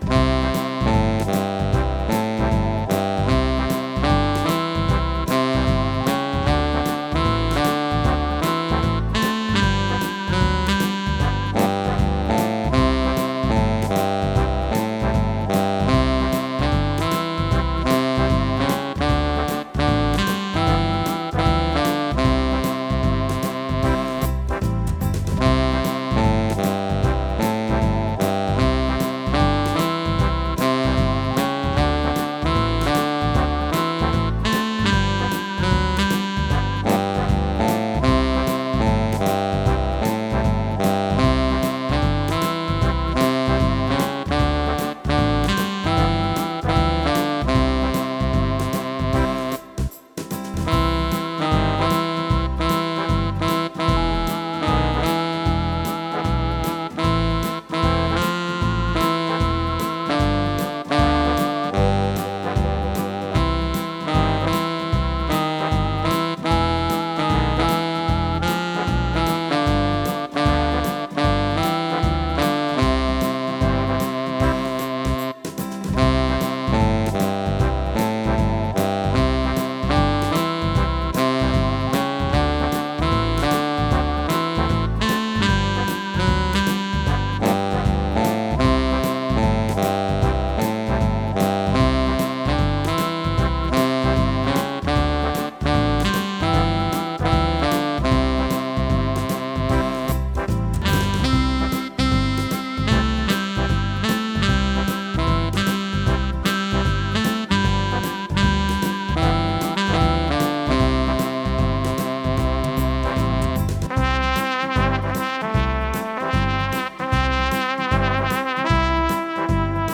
Here's the background music to the groom's bedroom: My keyboard is geared for dance music: swing, trance, ballroom etc.. So I didn't find it easy to make it play some likeness to chamber music.